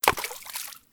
music are now 16bits mono instead of stereo
missed.wav